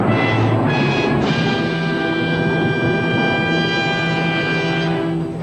Звук потрясенного суслика, не верящего своим глазам (драматический момент)